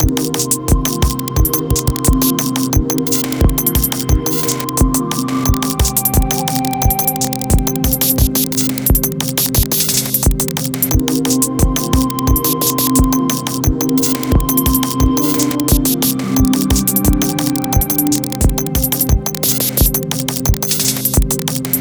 Saturday Night Groove for the Push 3 Gang: